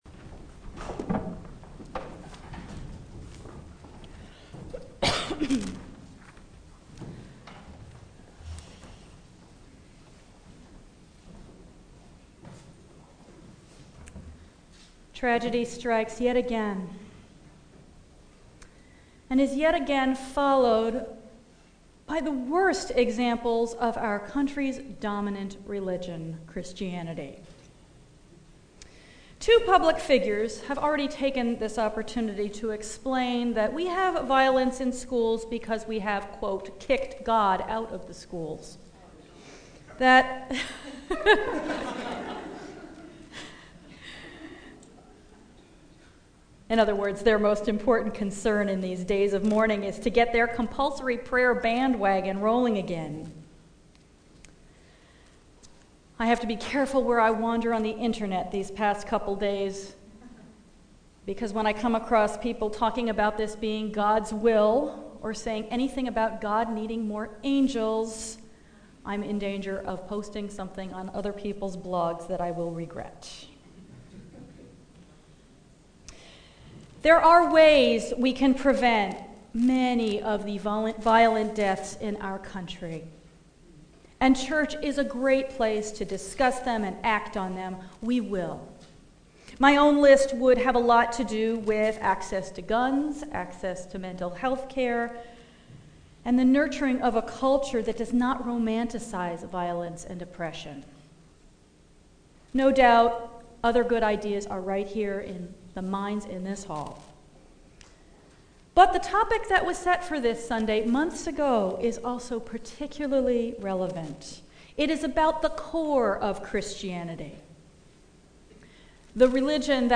Sermons, etc.